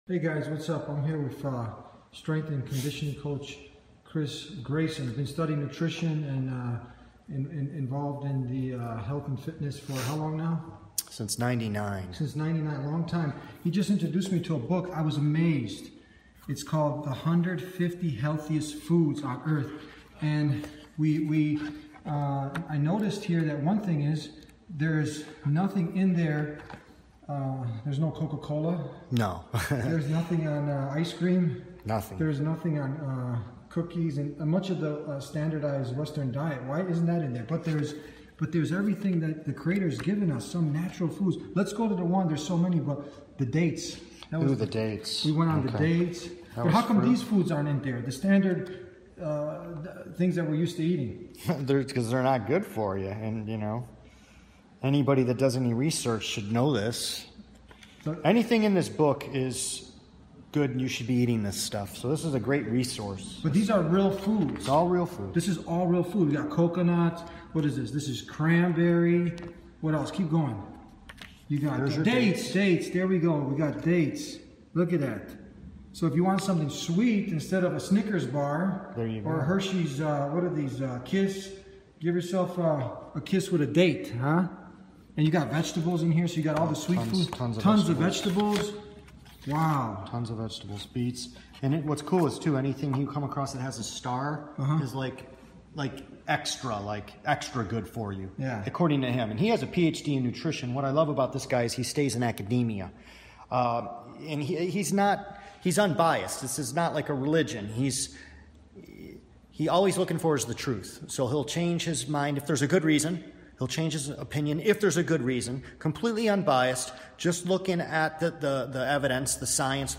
Through an engaging dialogue, the discussion transitions to the broader impact of the modern agricultural and pharmaceutical industries on public health.